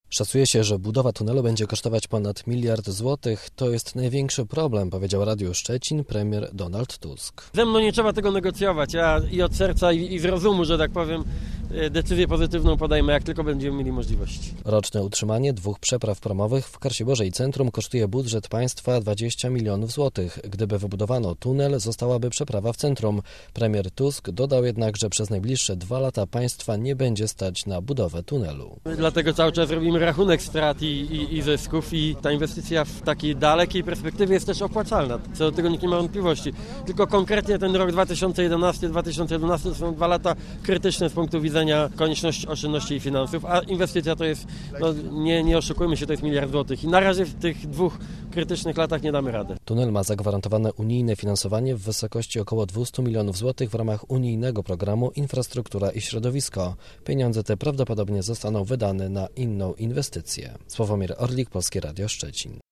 - Szacuje się, że budowa tunelu będzie kosztować ponad miliard złotych, to jest największy problem - powiedział Radiu Szczecin premier Donald Tusk.